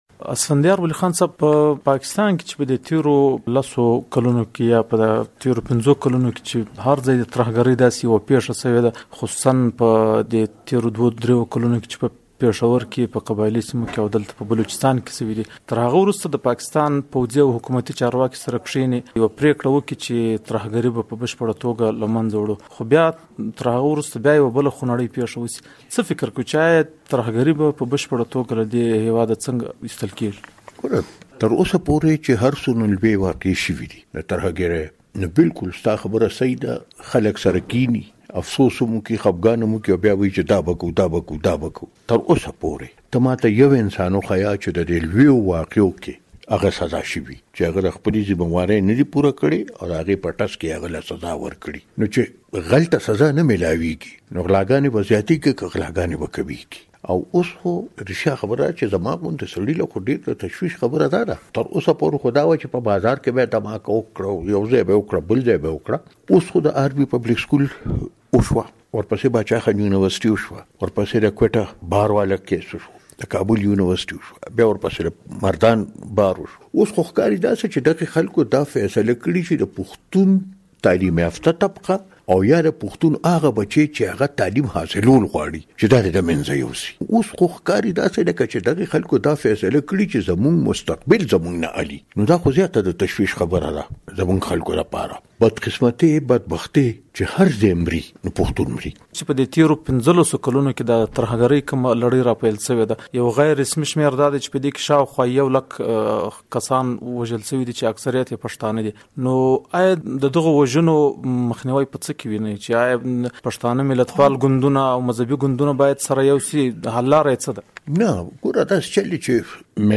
د عوامي نېشنل ګوند له مشر اسفندیار ولي خان سره مرکه